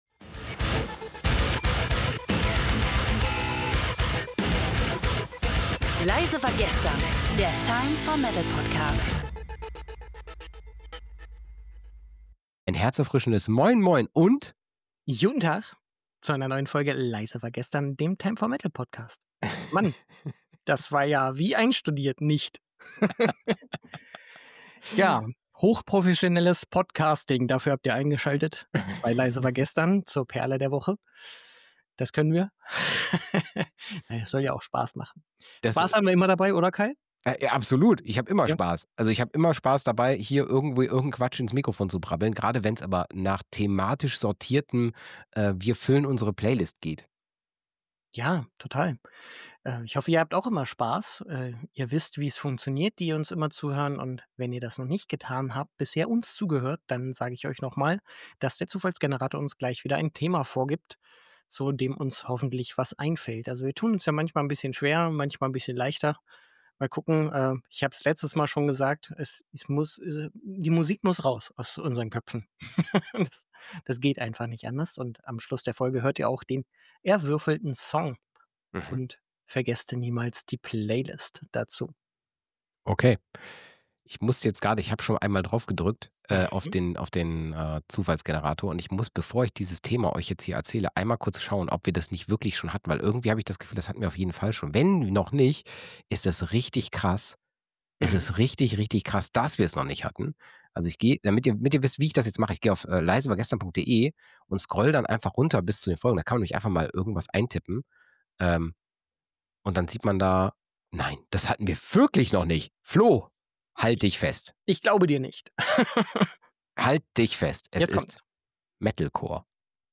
Das und mehr – natürlich mit viel Humor und ehrlicher Begeisterung!